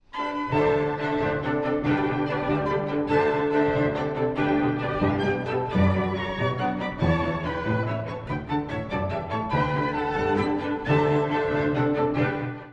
(第一主題) 古い音源なので聴きづらいかもしれません！
トリオでは一転して開放感と楽しげな雰囲気が広がります。
軽快な表面の下に、深い感情のうねりを感じさせます。